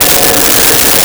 Nuclear Scanner
Nuclear Scanner.wav